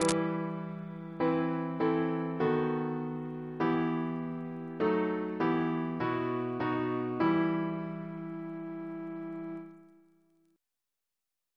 Single chant in E minor Composer: John Naylor (1838-1897), Organist of York Minster Reference psalters: H1940: 662; H1982: S197